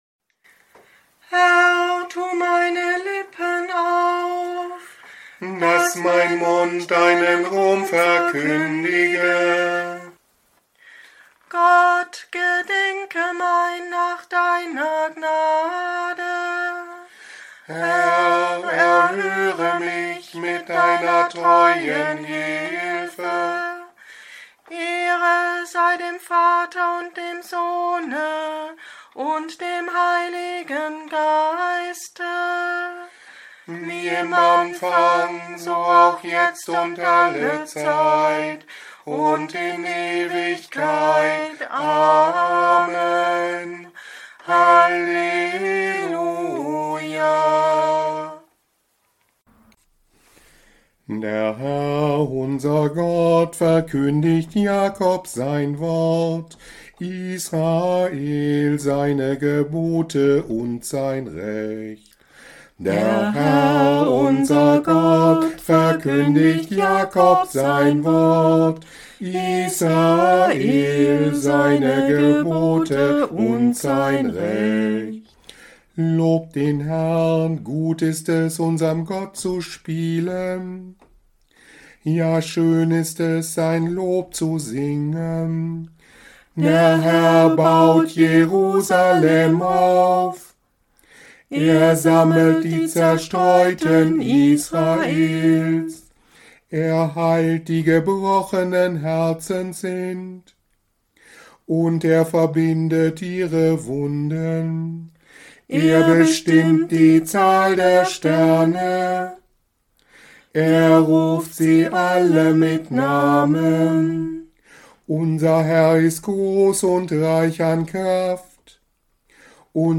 Morgengebet am Montag nach dem Sonntag Misericordias Domini 2025 (5. Mai) Nummern im Tagzeitenbuch: 330, 601, 609, 616, 617, 635+226, Raum zum persönlichen Gebet, EGb S. 328